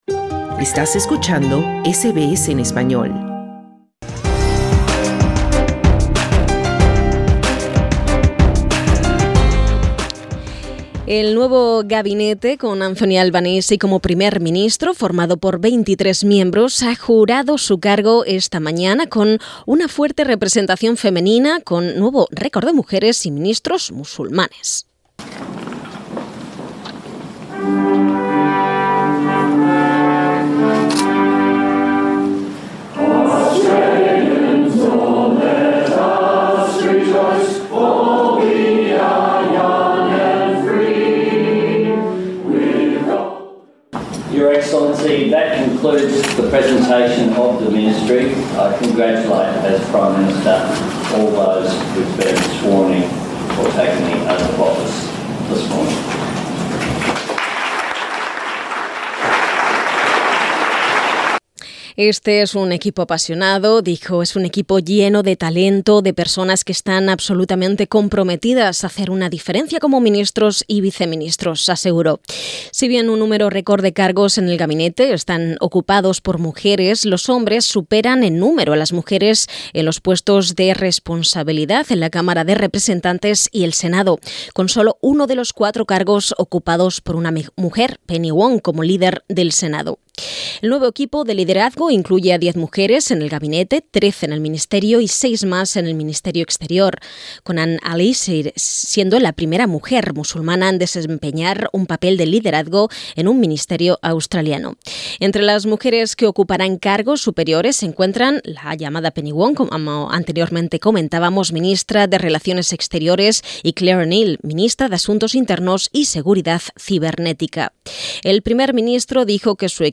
Escucha el reporte sobre el juramento del nuevo equipo de gobierno australiano presionando la imagen principal.